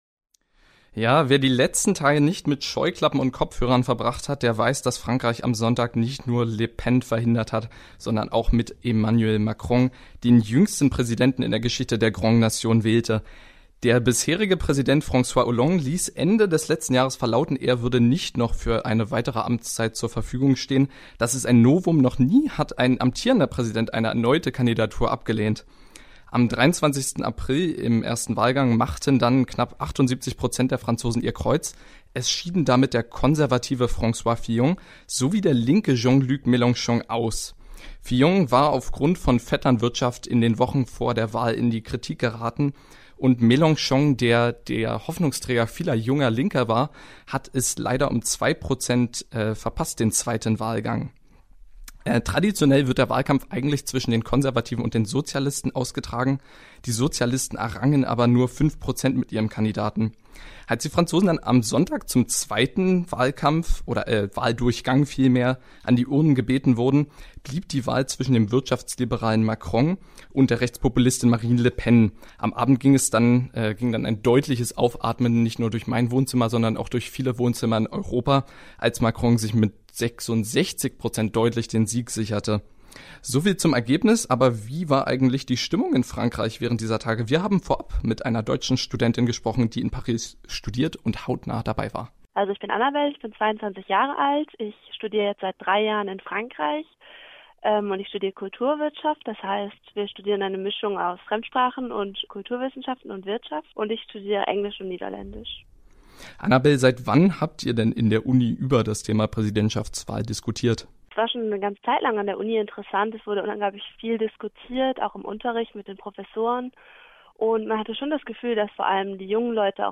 Frankreich-Wahl-Interviews-Mitschnitt-OHNE-MUSIK.mp3